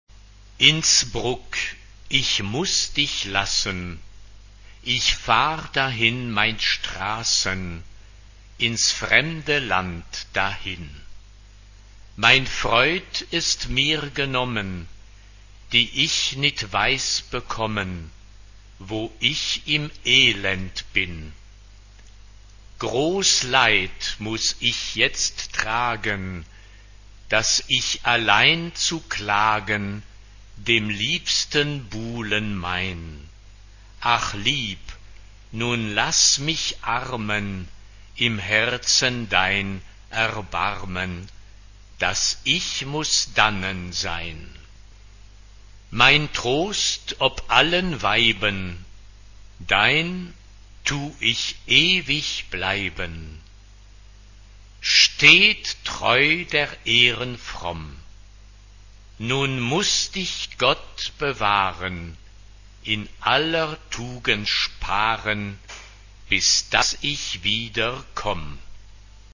SSAA (4 voices women) ; Full score.
Madrigal. Renaissance.
Type of Choir: SSAA (4 women voices )
Tonality: G major